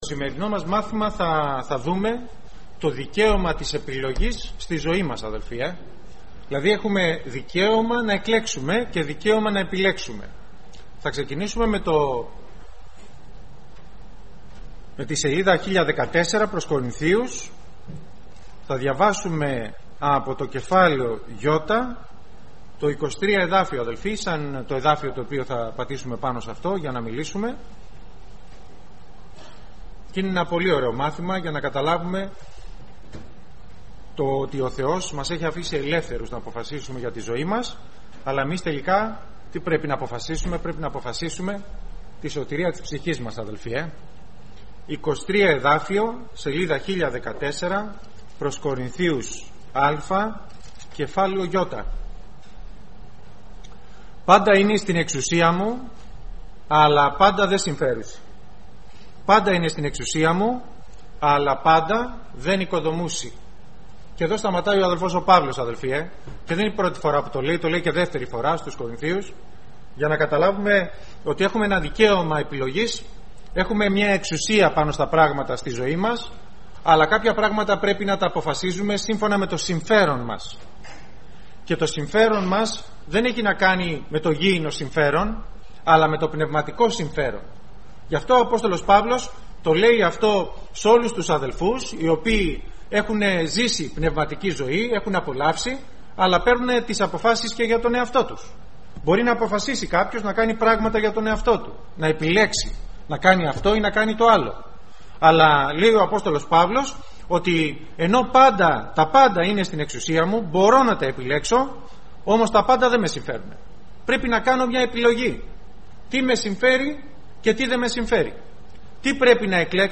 Κηρύγματα